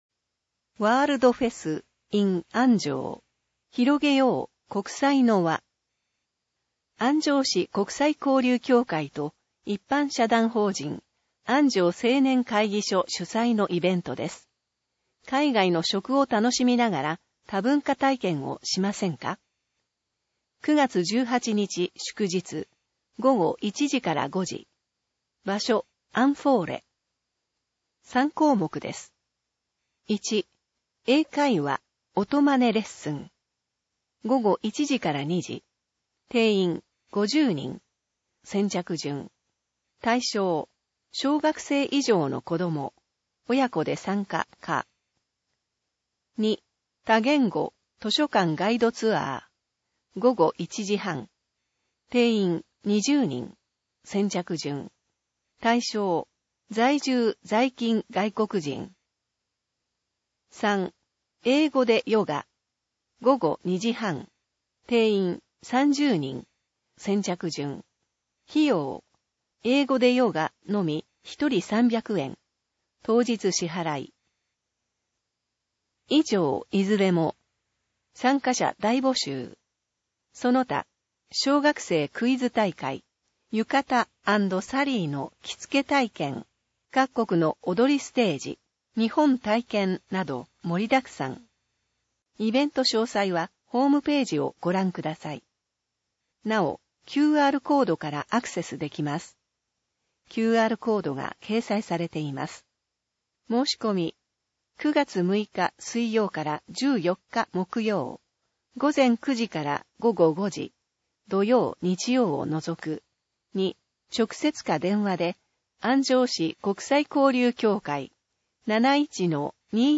なお、以上の音声データは、「音訳ボランティア安城ひびきの会」の協力で作成しています。